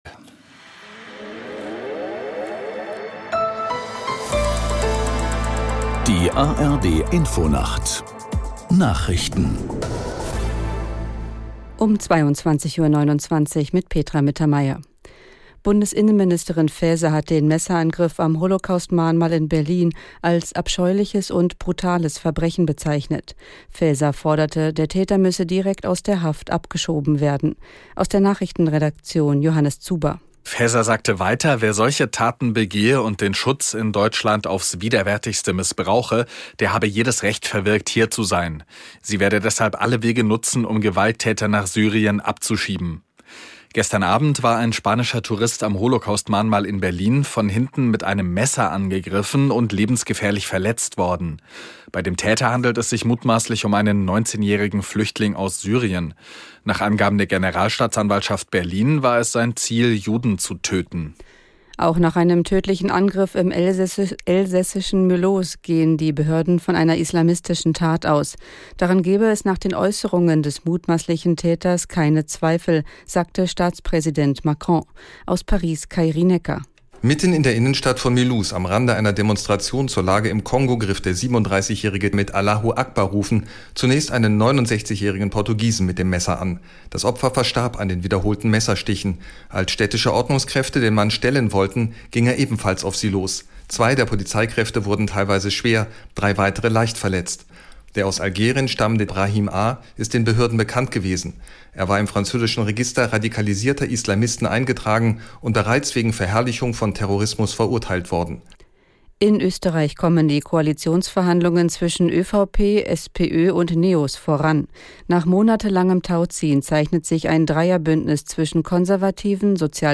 1 Nachrichten 3:22